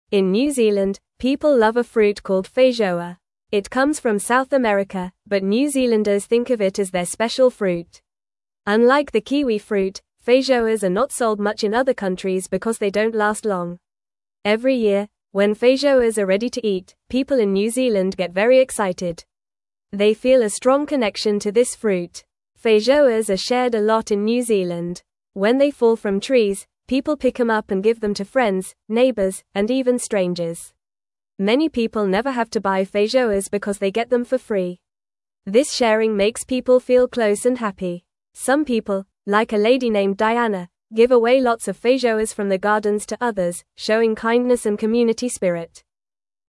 Fast